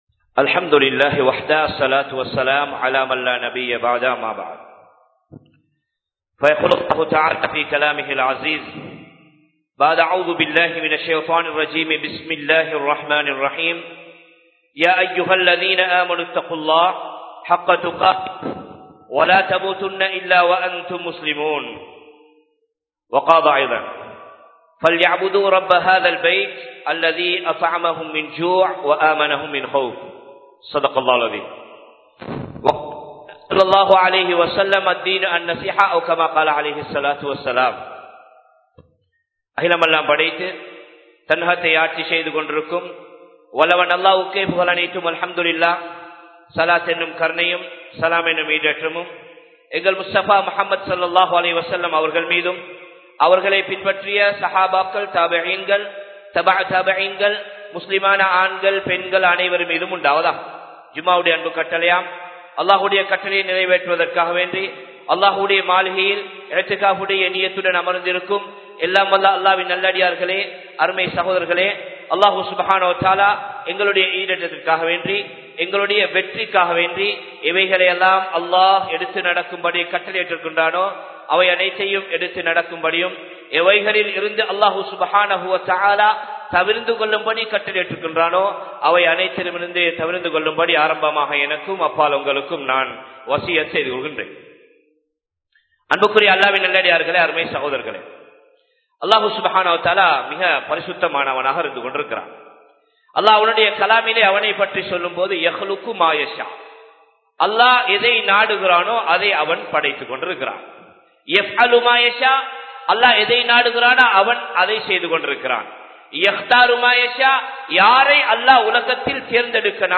தக்வா | Audio Bayans | All Ceylon Muslim Youth Community | Addalaichenai
Noor Jumua Masjidh